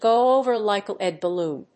アクセントgò over [《主に英国で用いられる》 dówn] like a léad ballóon